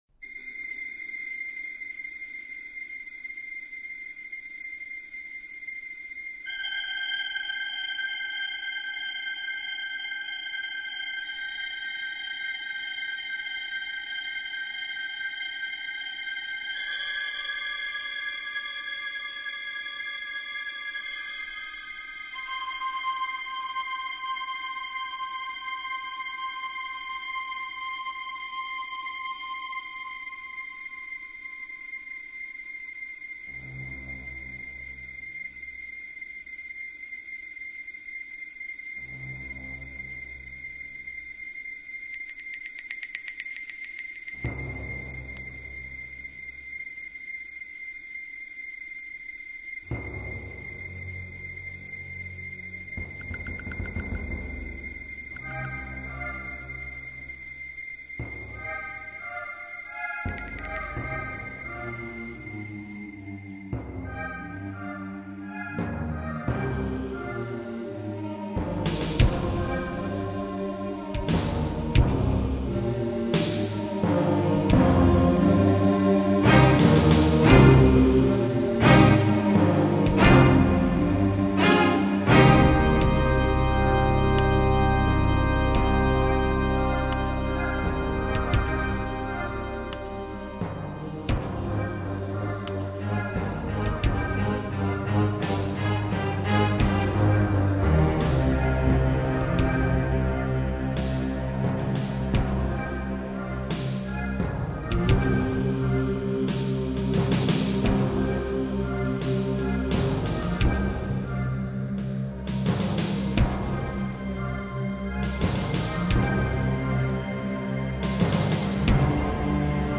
Bande originale du film